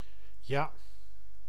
Ääntäminen
IPA: [jaː]